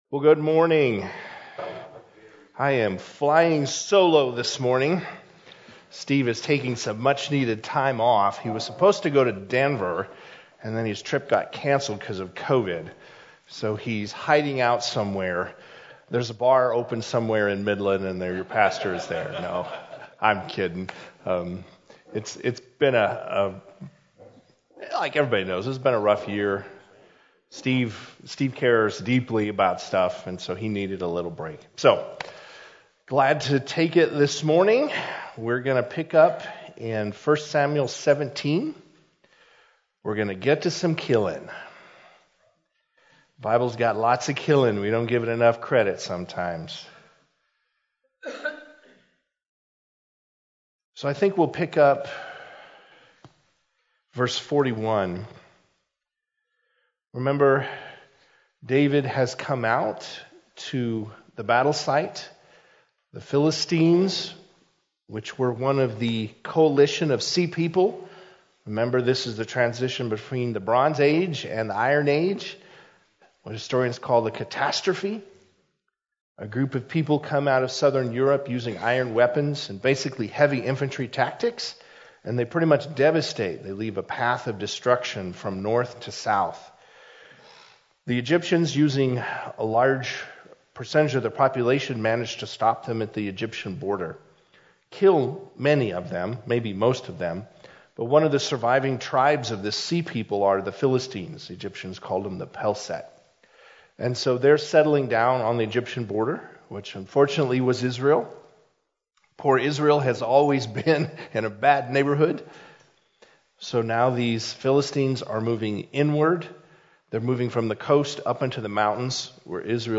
Men’s Breakfast Bible Study 2/9/21